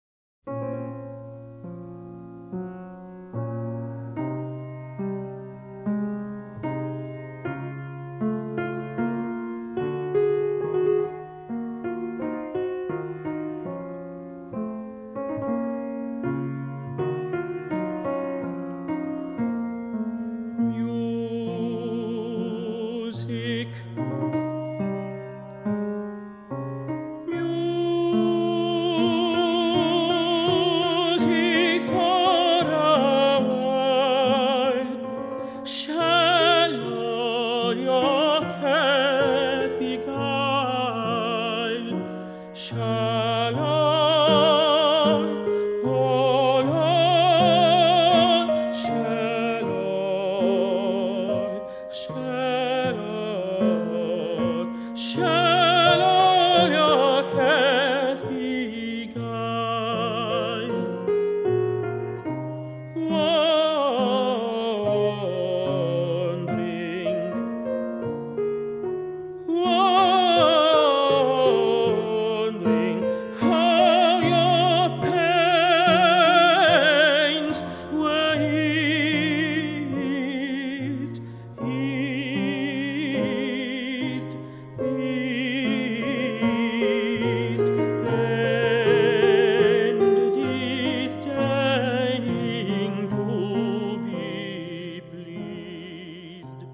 É uma seleção de canções de câmara